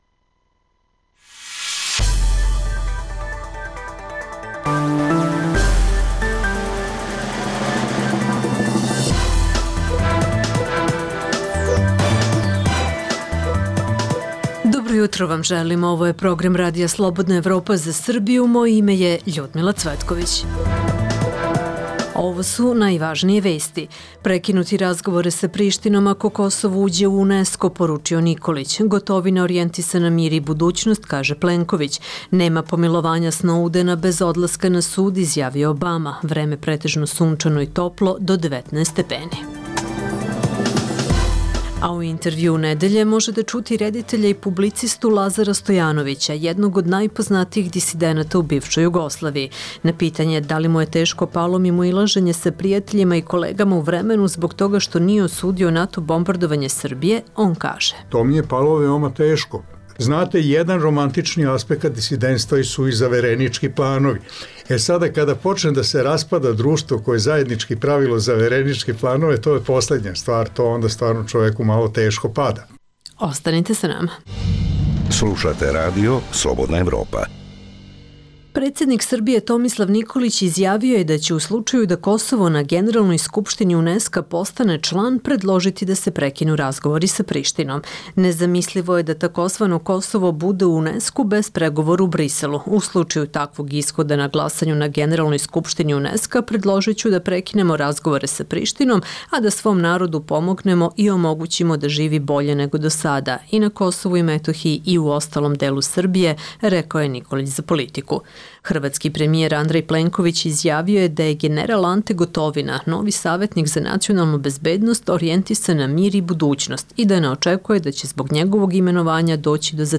Uz dnevne aktuelnosti čućete i Intervju nedelje sa rediteljem i publicistom Lazarom Stojanovićem, jednim od najpoznatijih disidenata u bivšoj Jugoslaviji.